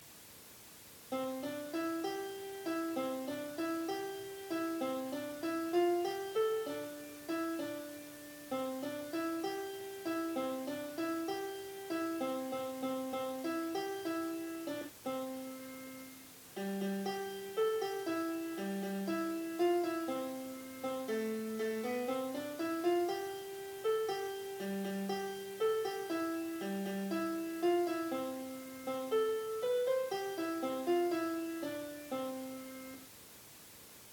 Dutch Saint Nicholas song with music.